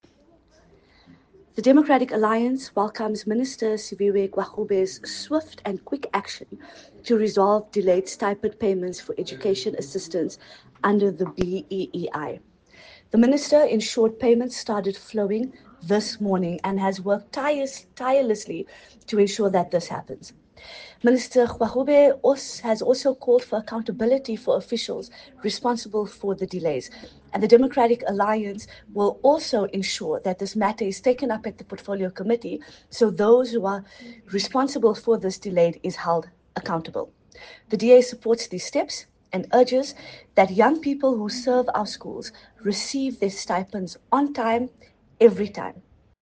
Soundbite by Nazley Sharif MP.